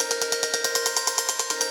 Index of /musicradar/shimmer-and-sparkle-samples/140bpm
SaS_Arp01_140-C.wav